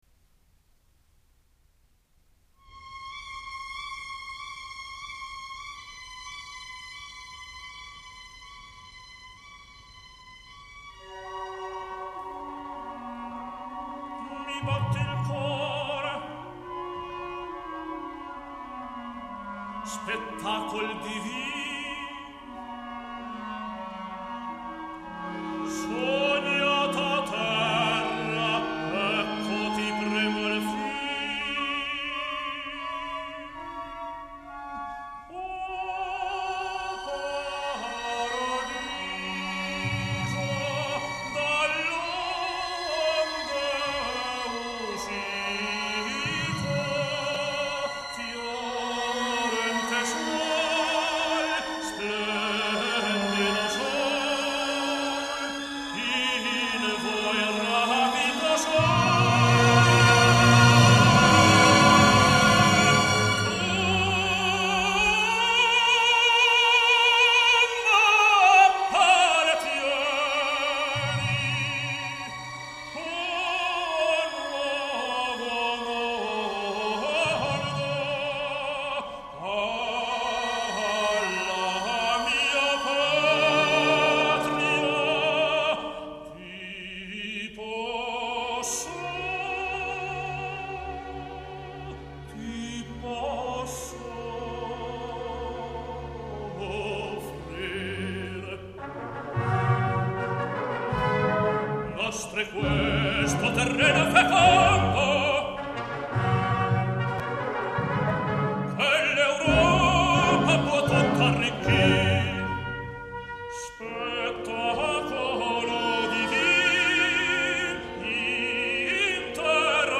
Сегодня исполняется 87 лет великому итальянскому тенору Карло Бергонци!